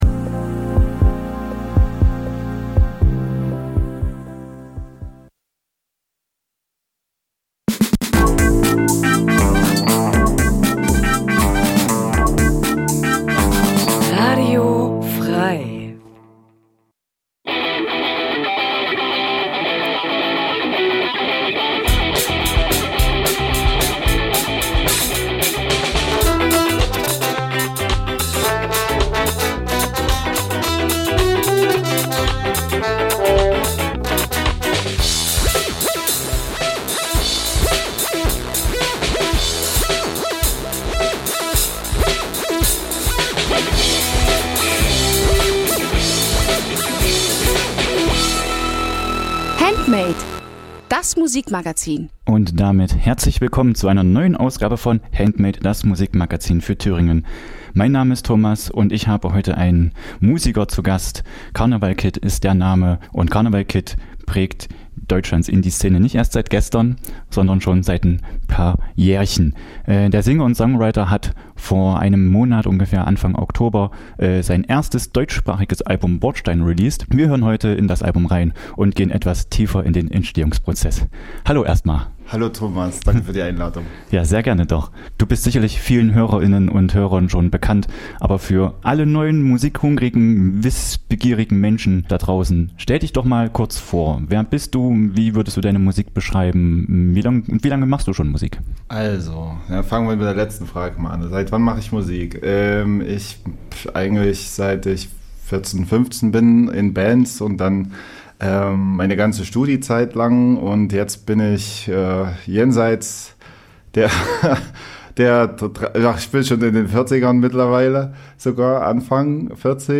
Jeden Donnerstag stellen wir euch regionale Musik vor und scheren uns dabei nicht um Genregrenzen.